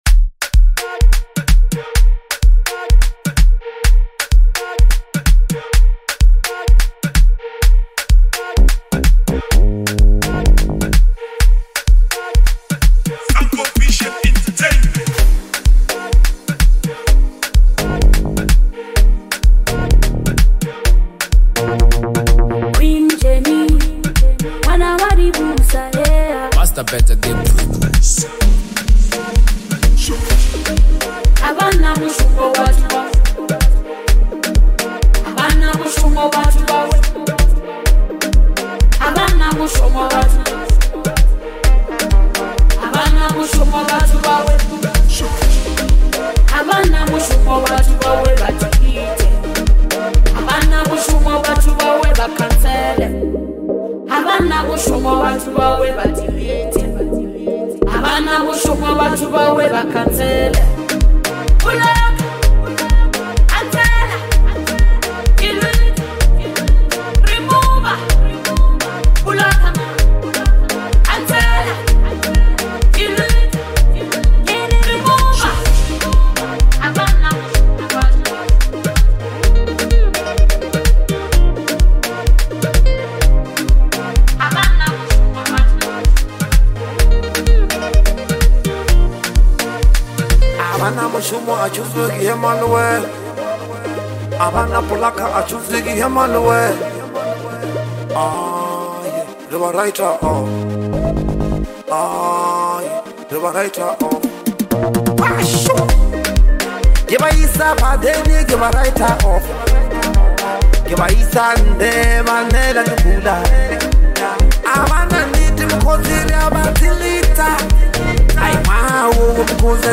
deeply meaningful and rhythmic track